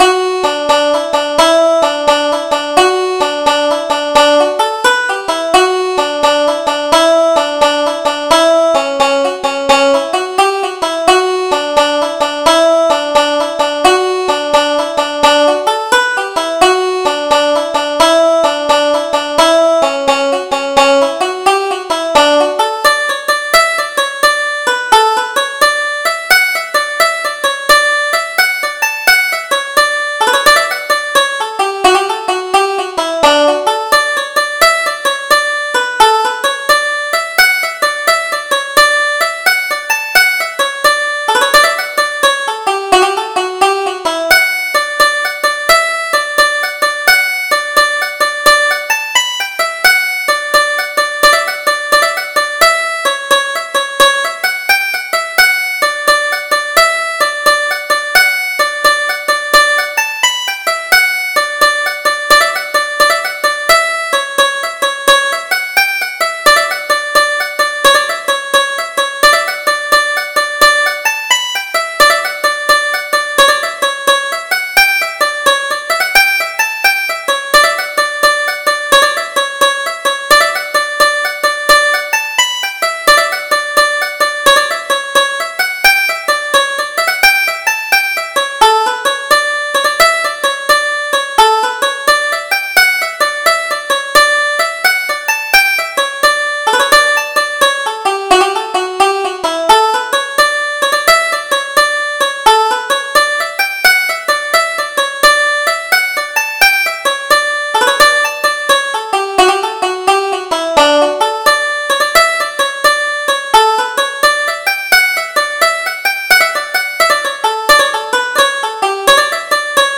Double Jig: Wallop the Potlid